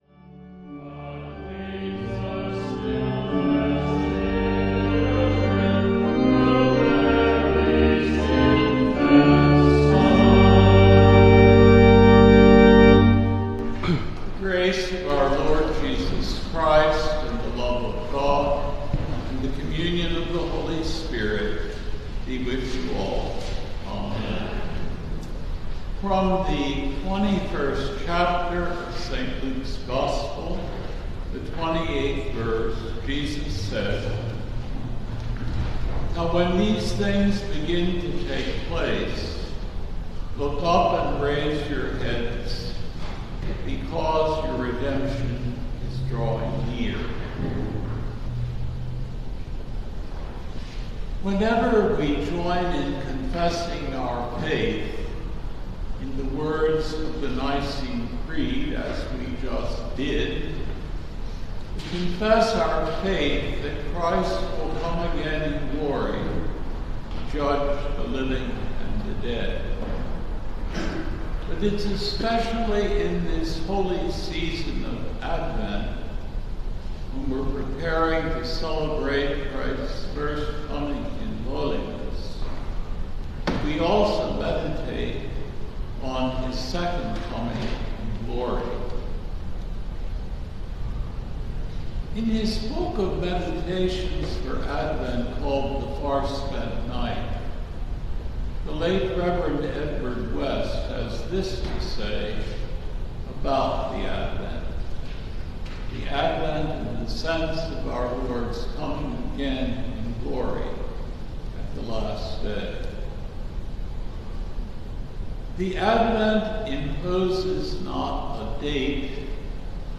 The Second Sunday in Advent December 4, 2022 AD